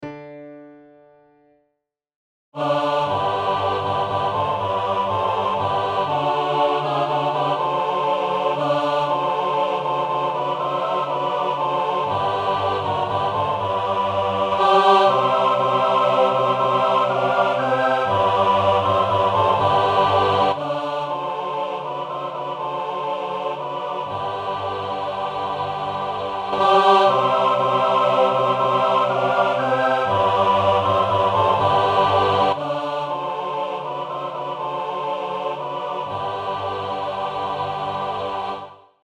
Bajazzo, Deutsches Volkslied
Sie dienen ausschliesslich dem Erlernen der Noten im Kontext aller Stimmen und der Begleitung. Tempo- und Lautstärken-Variationen sowie andere Ausdrucksvorgaben wurden nur wenig berücksichtigt.
BajazzoTutti.mp3